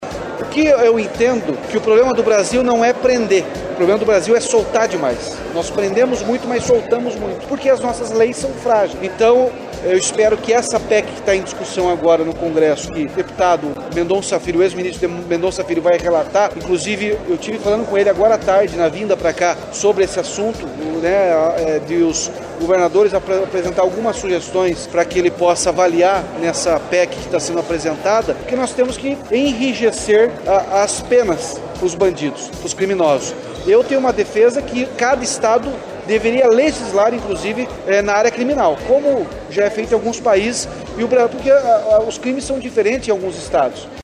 O governador do Paraná, Ratinho Junior (PSD), afirmou que está em diálogo com o relator da PEC da Segurança Pública, deputado federal Mendonça Filho (União Brasil-PE), para propor ações que possam endurecer as leis e contribuir com o combate à violência no Brasil.
A afirmação foi feita nesta quinta-feira (30), durante coletiva de imprensa após o leilão do Lote 6 de rodovias na B3, em São Paulo.